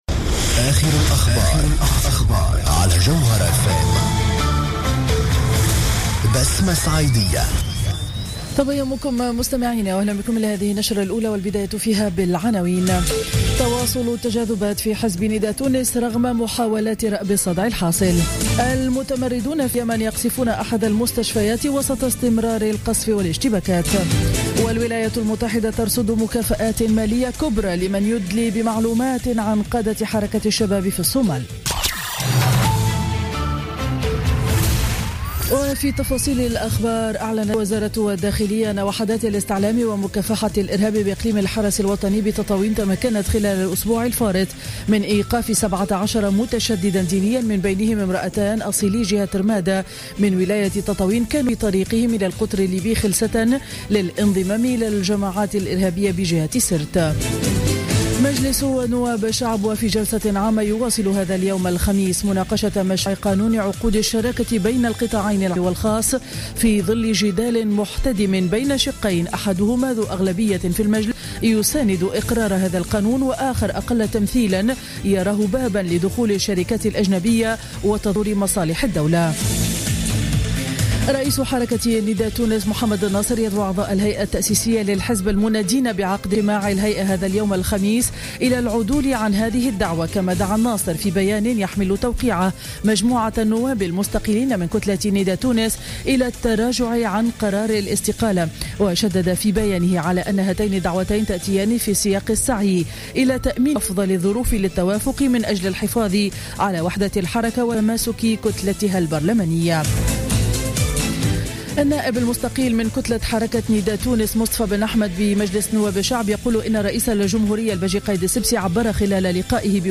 نشرة أخبار السابعة صباحا ليوم الخميس 12 نوفمبر 2015